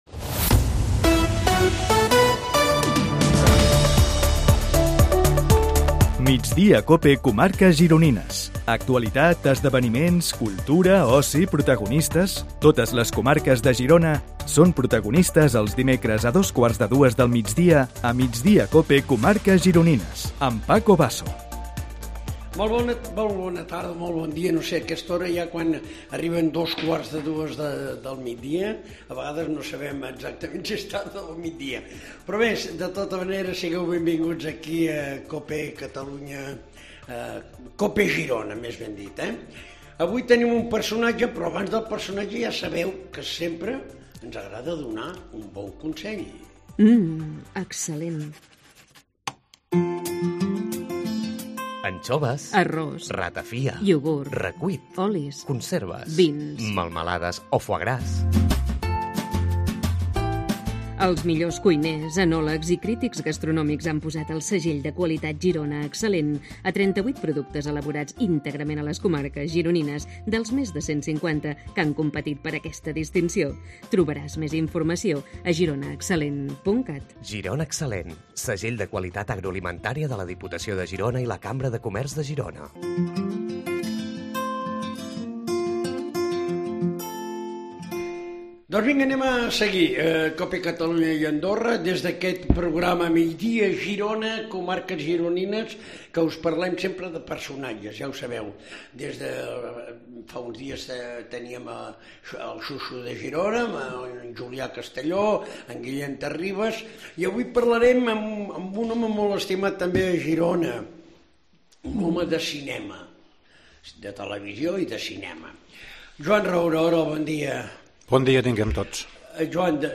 AUDIO: Entrevistem els protagonistes de l'actualitat a les comarques gironines